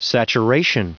Prononciation du mot saturation en anglais (fichier audio)
Prononciation du mot : saturation